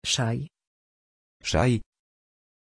Aussprache von Shay
pronunciation-shay-pl.mp3